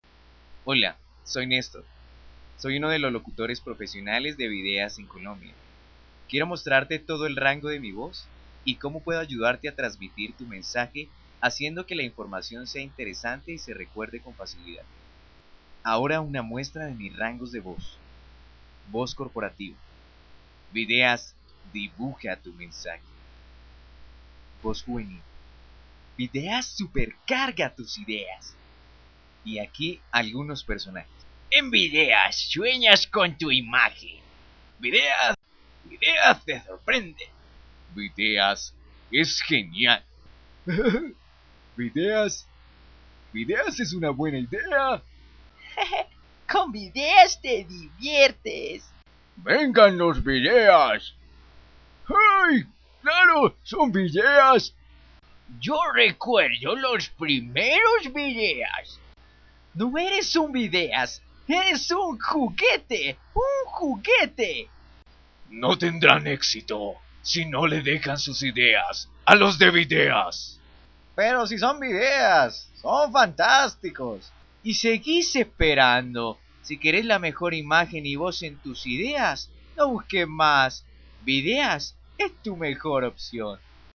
Locutor Comercial de Bogota Colombia.
Sprechprobe: Industrie (Muttersprache):